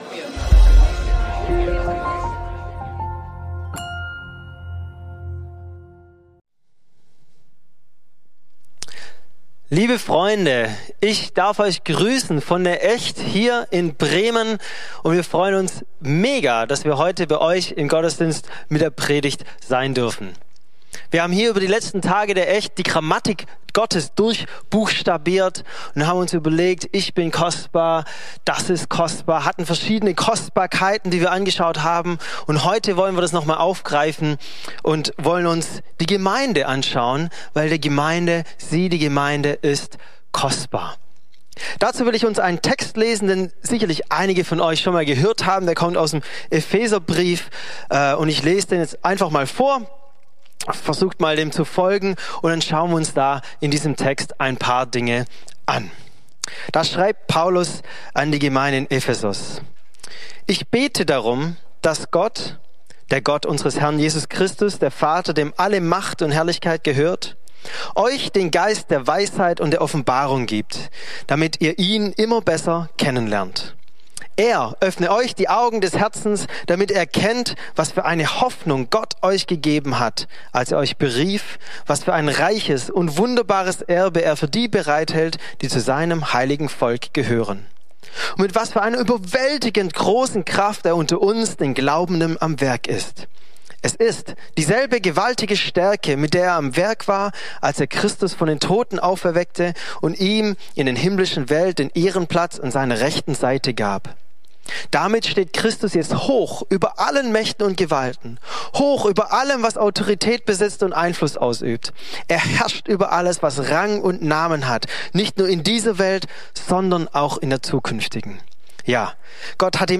Thema: Predigt von der ECHT!-Konferenz - Sie ist kostbar Bibeltext: Epheser 1, 17-23